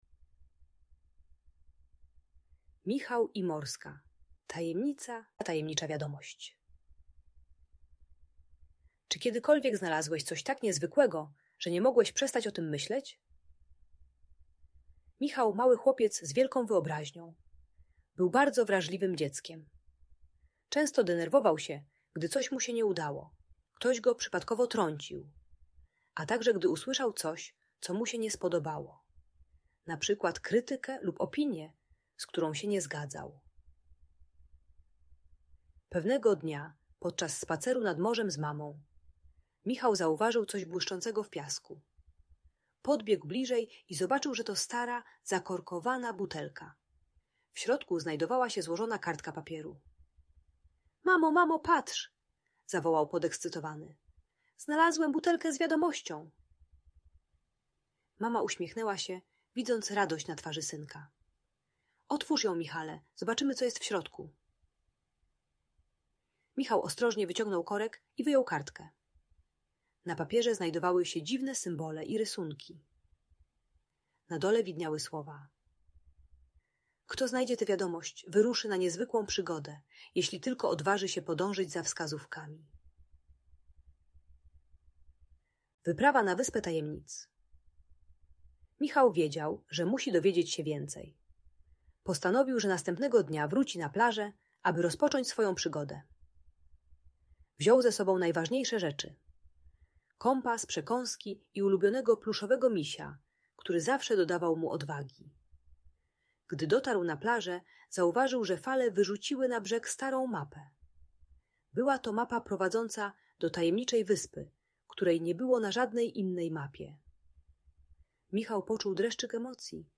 Michał i Morska Tajemnica - story o przygodzie i emocjach - Audiobajka dla dzieci